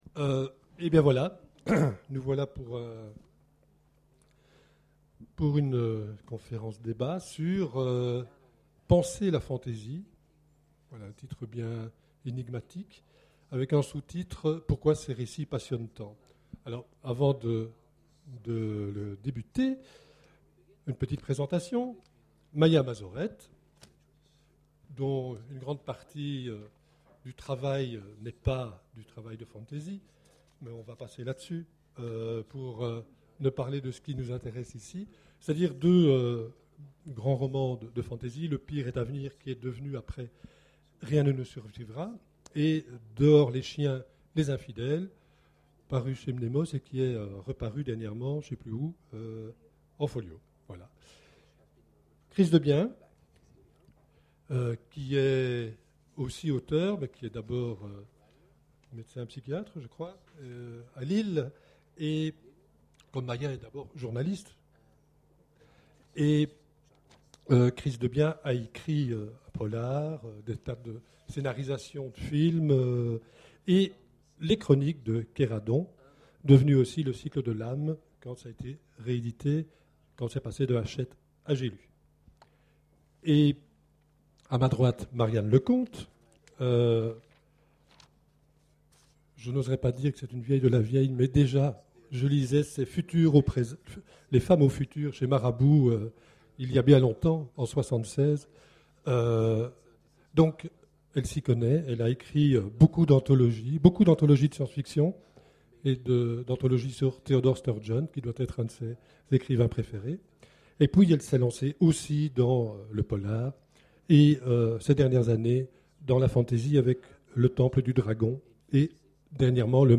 Imaginales 2011 : Conférence Penser la fantasy, pourquoi ces récits passionnent tant ?